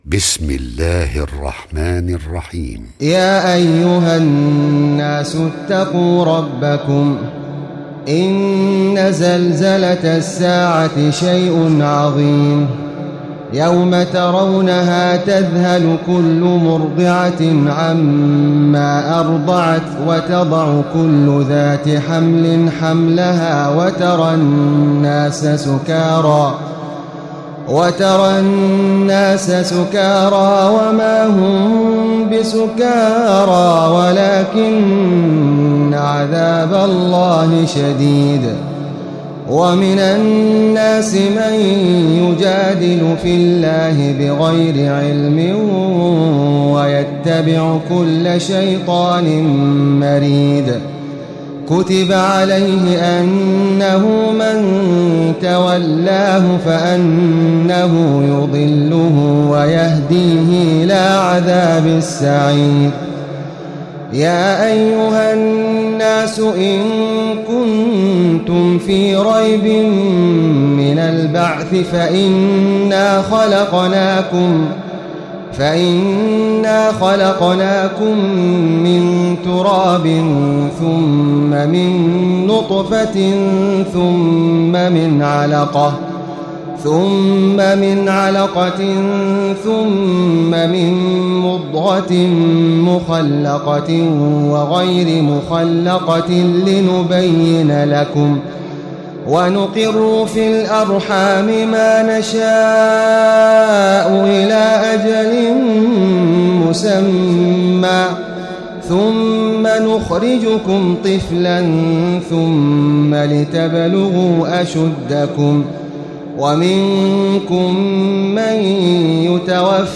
تراويح الليلة السادسة عشر رمضان 1436هـ سورة الحج كاملة Taraweeh 16 st night Ramadan 1436H from Surah Al-Hajj > تراويح الحرم المكي عام 1436 🕋 > التراويح - تلاوات الحرمين